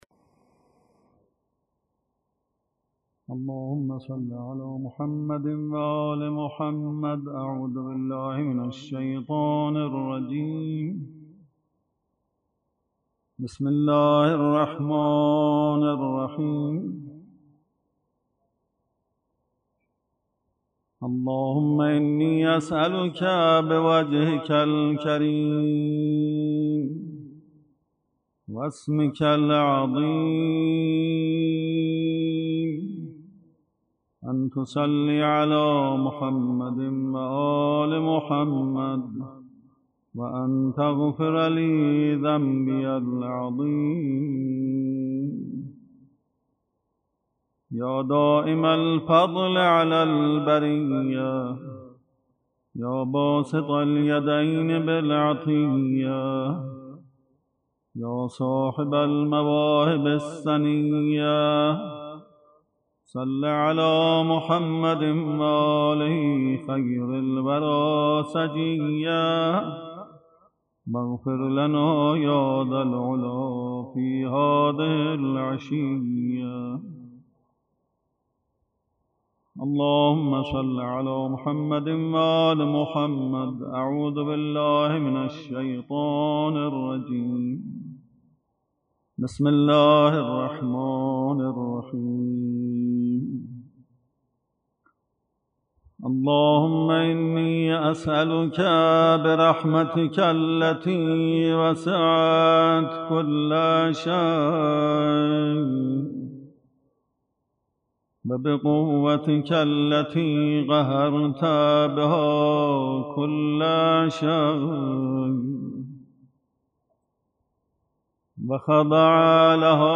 قراءة دعاء كميل 1